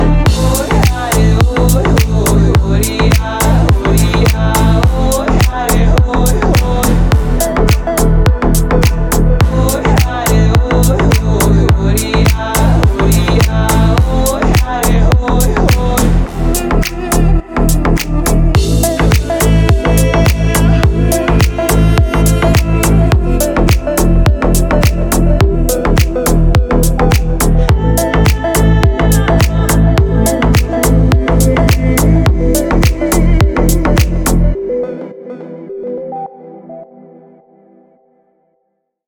deep house
восточные мотивы
красивая мелодия